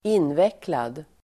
Uttal: [²'in:vek:lad]